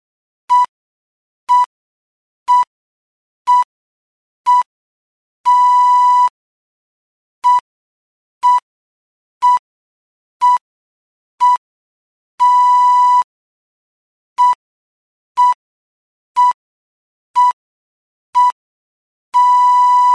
Polyphonic Ringtone
pips1c_poly.mp3